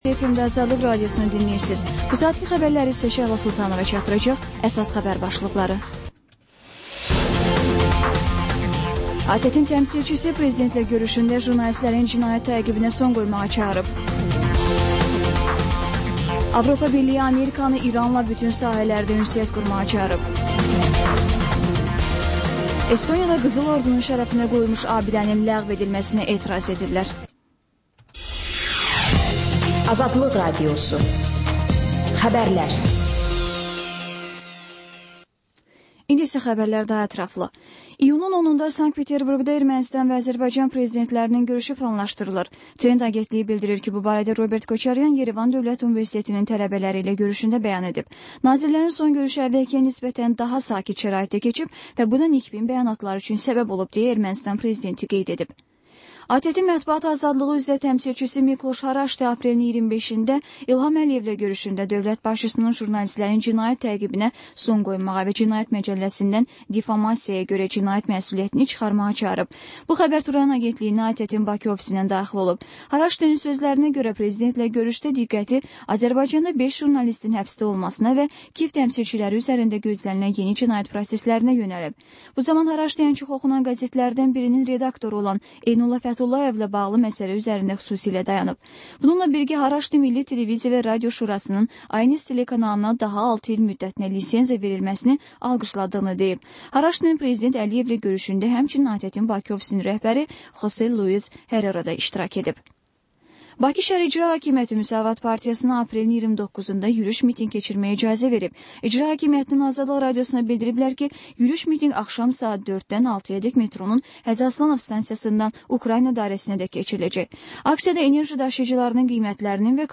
Xəbərlər, ardınca XÜSUSİ REPORTAJ rubrikası: Ölkənin ictimai-siyasi həyatına dair müxbir araşdırmaları. Sonda isə TANINMIŞLAR verilişi: Ölkənin tanınmış simalarıyla söhbət